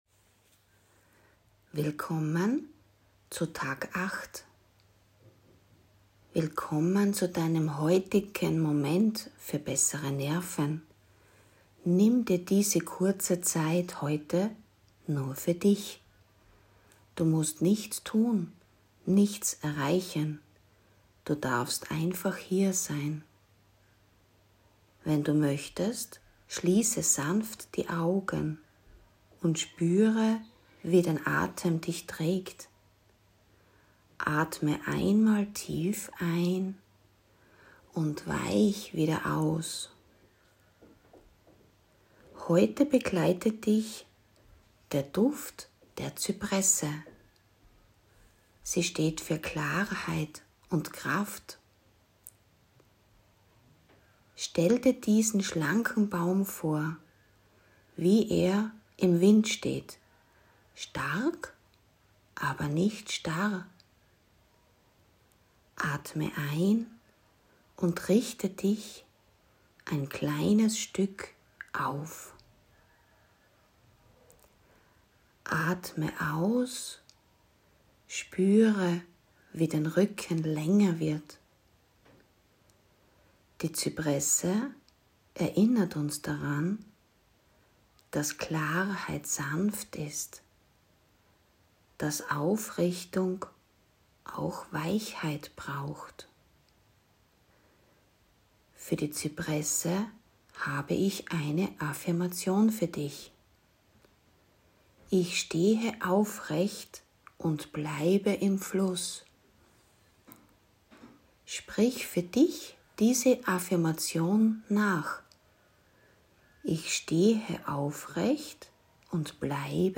Aufrichten ohne Härte – ruhig & klar.